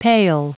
Prononciation du mot pail en anglais (fichier audio)
Prononciation du mot : pail